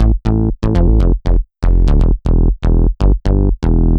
Index of /musicradar/french-house-chillout-samples/120bpm/Instruments
FHC_MunchBass_120-A.wav